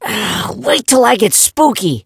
gus_hurt_vo_06.ogg